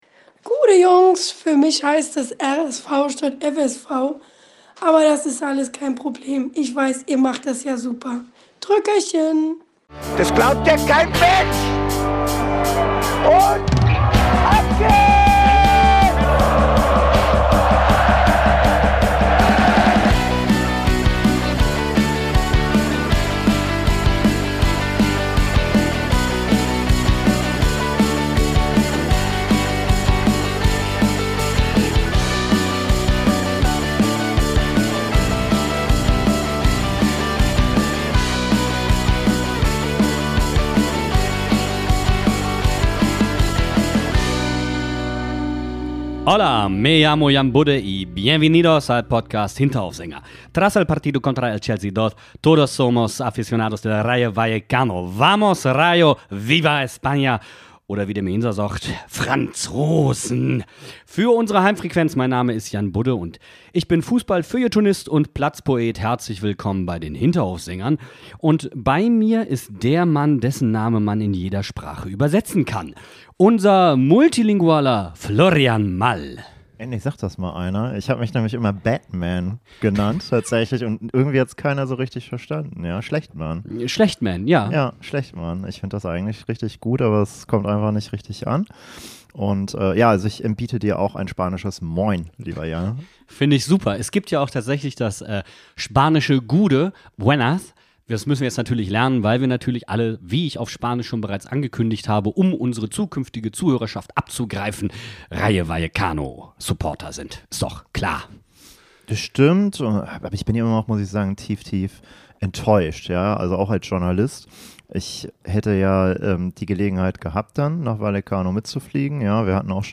Selbst wenn kurz mal die Ohren schmerzen: reinhören lohnt sich.